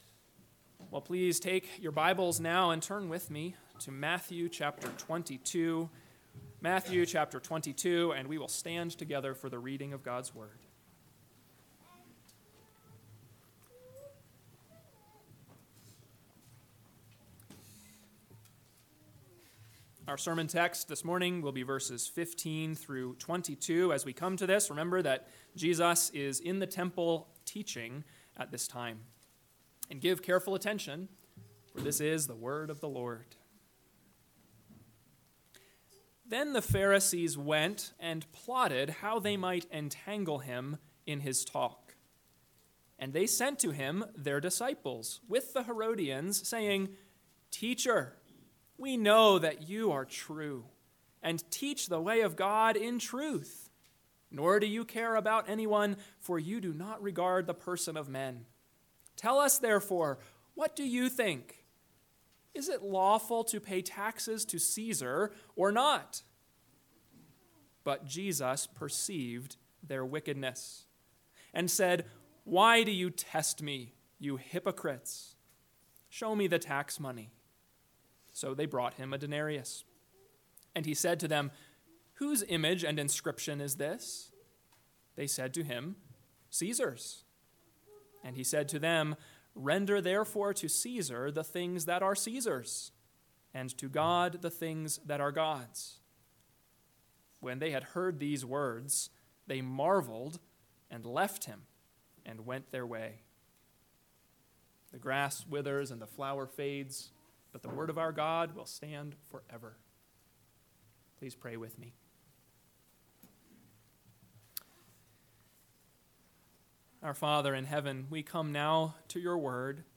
AM Sermon – 10/13/2024 – Matthew 22:15-22 – Northwoods Sermons